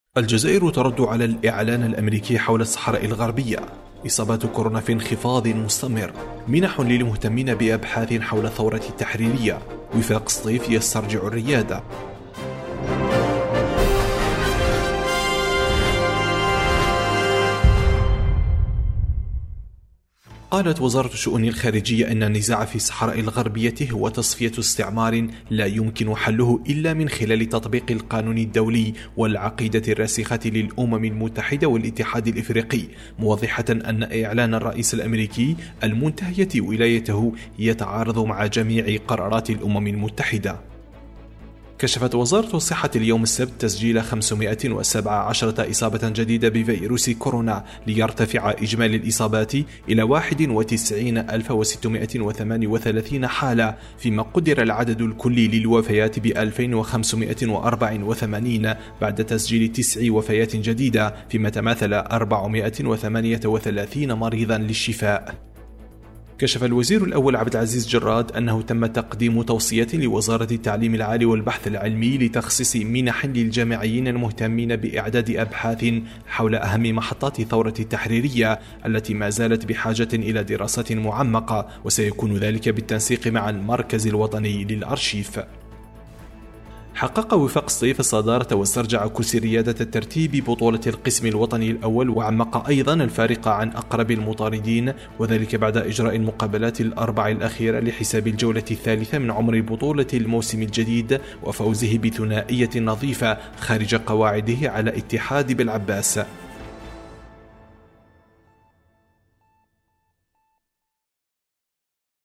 النشرة اليومية: الجزائر ترد..